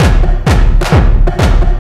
ELECTRO 02-L.wav